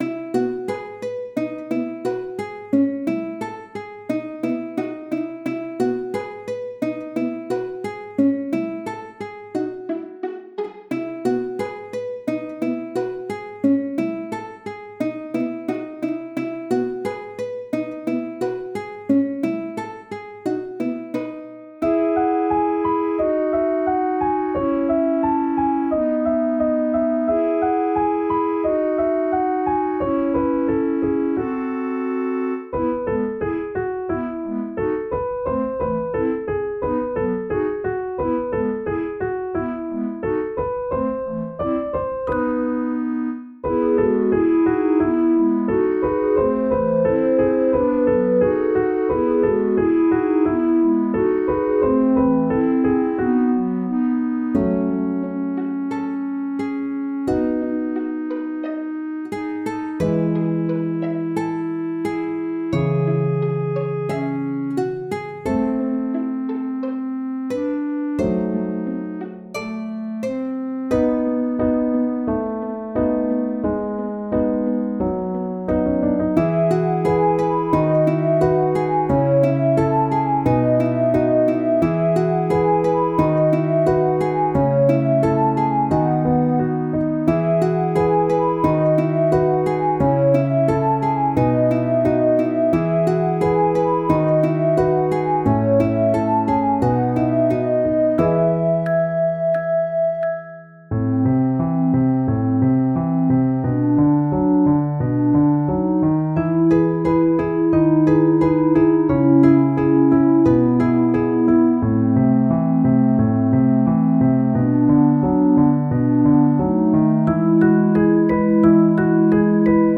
全体的にほわほわしています
ゆったり、いやし、おだやか